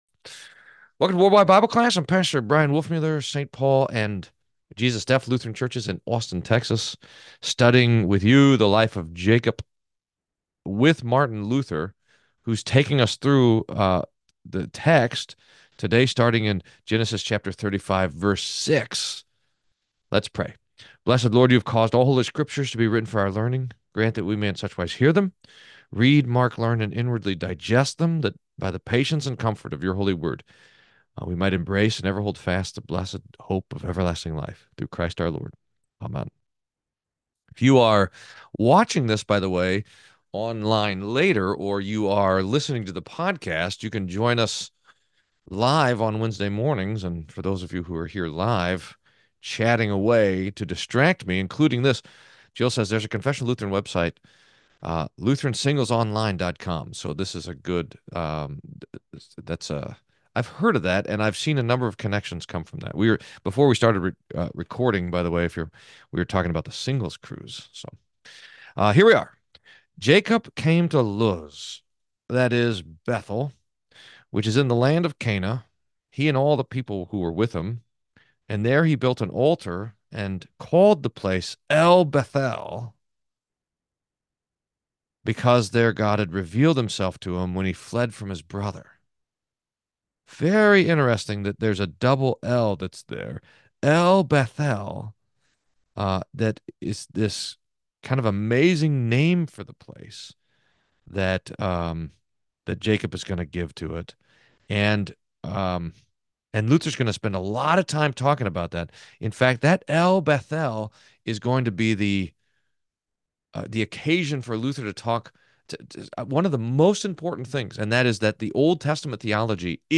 World-Wide Bible Class